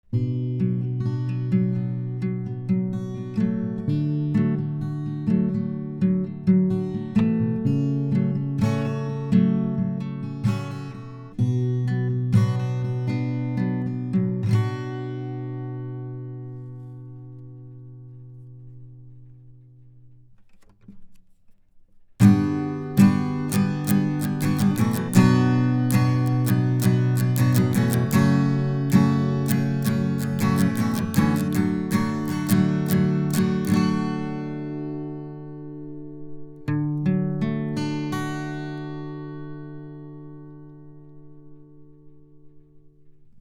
The recordings are unprocessed, at the same Gain level.
Lewitt LCT 640 TS – cardioid, guitar recording:
lewitt-lct-640-ts-guitar.mp3